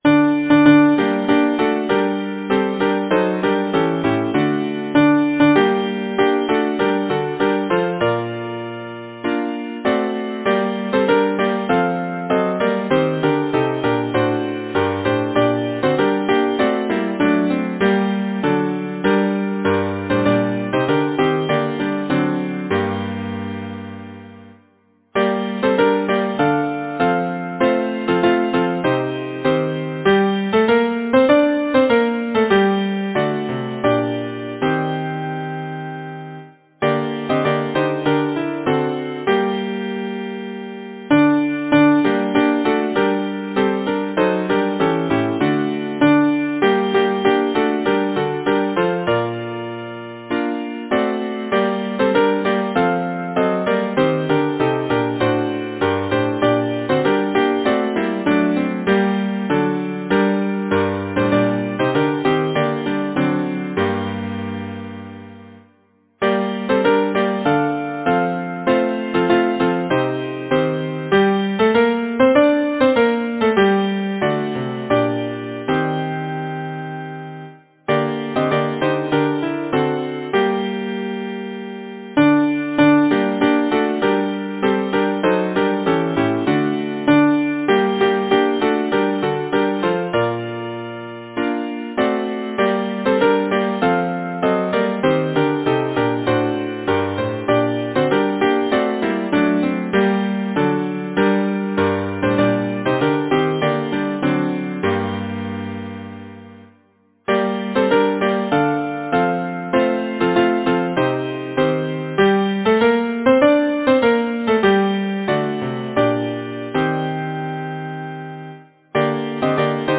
Title: The cruiskeen lawn Composer: Anonymous (Traditional) Arranger: William Rhys-Herbert Lyricist: Number of voices: 4vv Voicing: SATB Genre: Secular, Partsong, Folksong
Language: English Instruments: A cappella